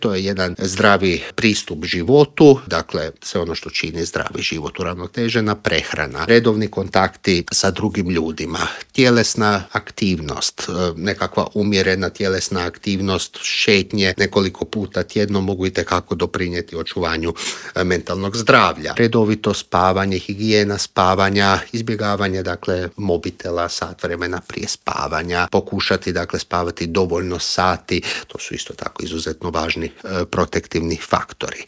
ZAGREB - Najdepresivniji je dan u godini pa smo u studiju Media servisa razgovarali o mentalnom zdravlju.